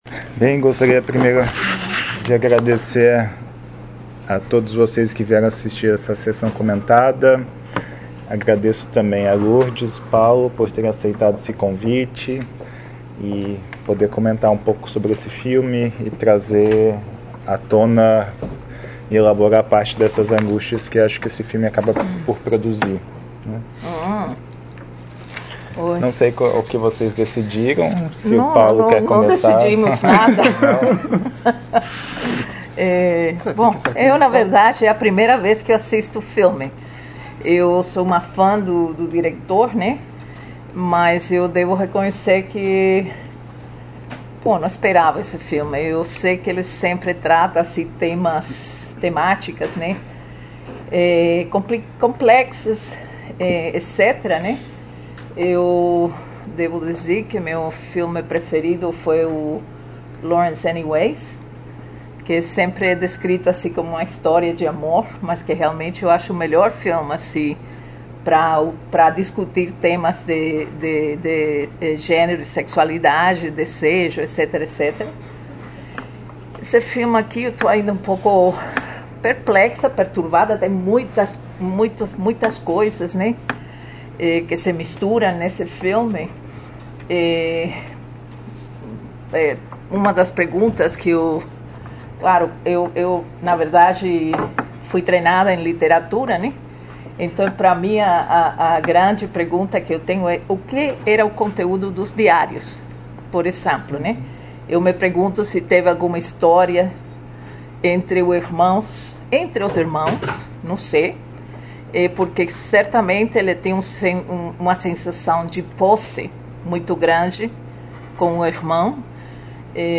Nesta seção você encontrará os áudios dos comentários realizados sobre cada um dos filmes apresentados nas edições do Projeto Cinema Mundo.